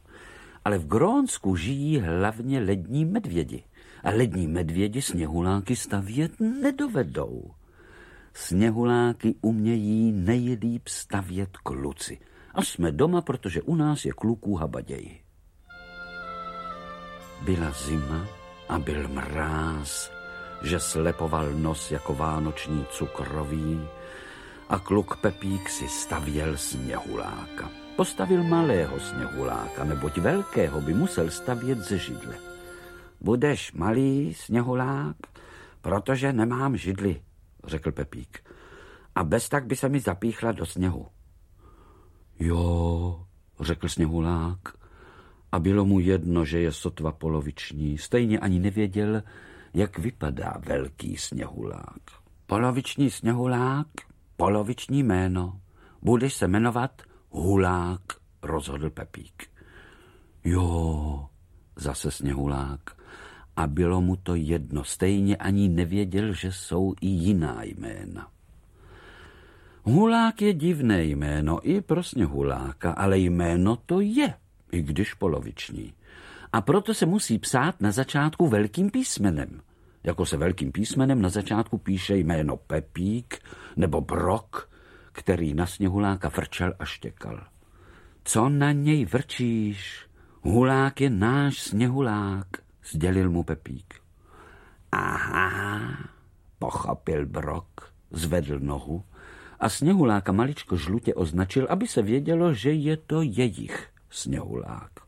Šťastné a veselé... 9 pohádek pod stromeček audiokniha
Ukázka z knihy
• InterpretVlastimil Brodský, Jiřina Bohdalová, Petr Nárožný